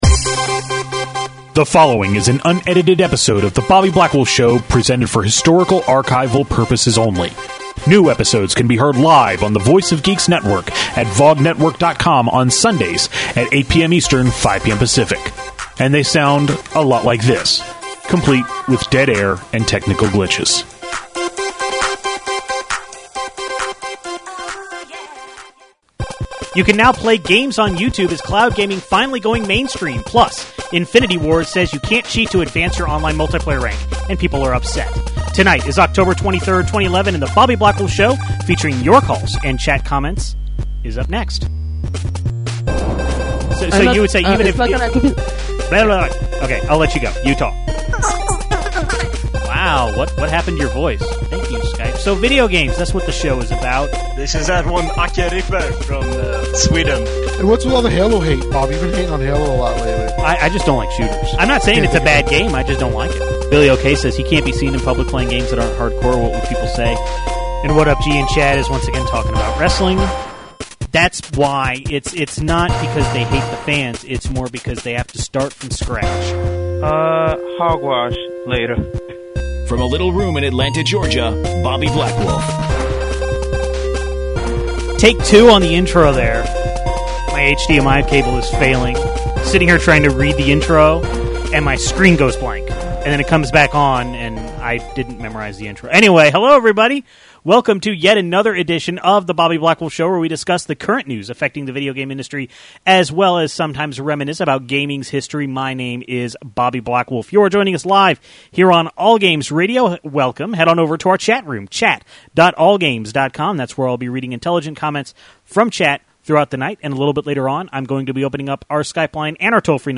Please keep this in mind when you hear long awkward pauses that are typically edited out of the podcast. In this episode, we talk about the World of Warcraft Annual Pass, YouTube cloud gaming, lack of Catwoman codes in some copies of Batman: Arkham City, and how Infinity Ward is removing progression from dedicated servers.